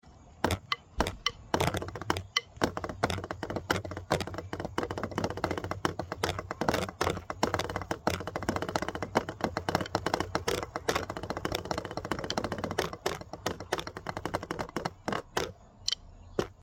(Part 2) Of Snare Beats Sound Effects Free Download